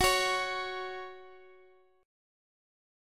Listen to Gb5 strummed